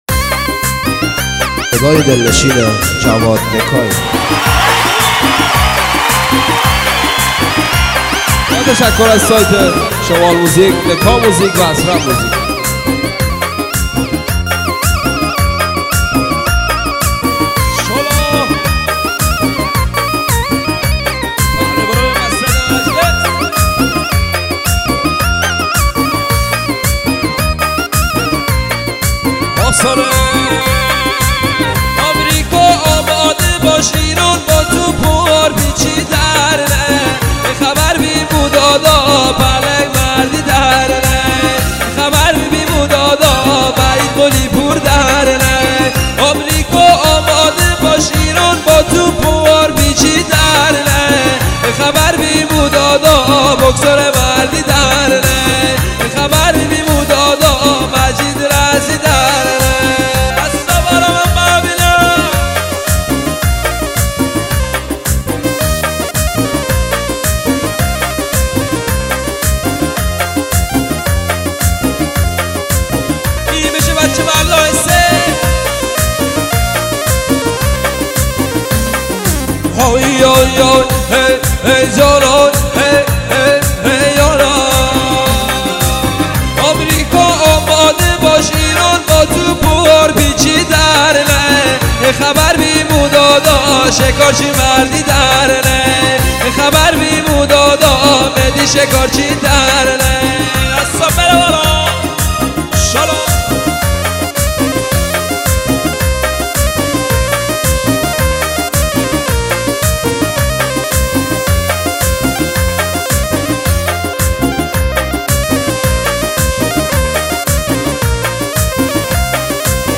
آهنگ شمالی